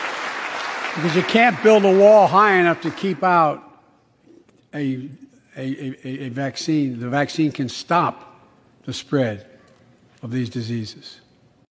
Bidens Rede zur Impfung